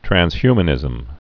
(trăns-hymə-nĭzəm, trănz-)